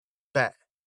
• bat（こうもり）：bæt → bet
🇬🇧発音：bat（こうもり）
bat-コックニー訛り.mp3